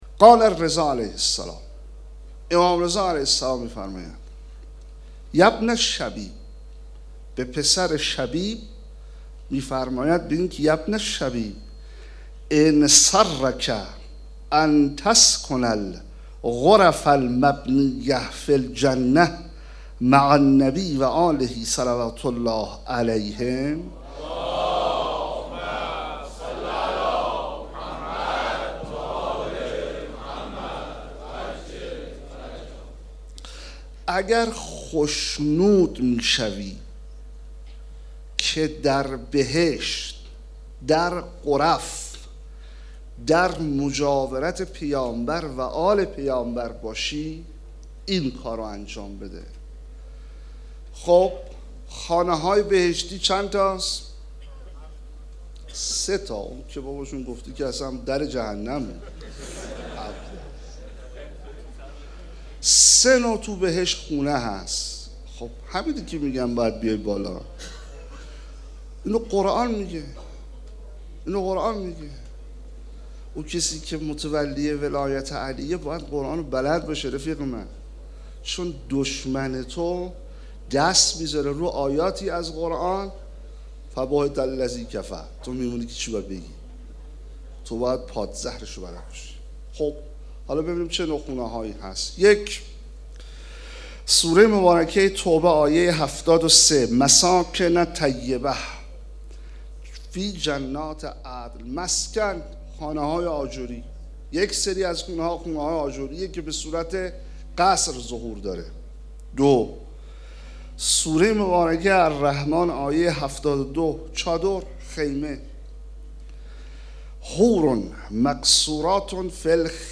شب اول محرم 1436 - هیات رایه العباس B > سخنرانی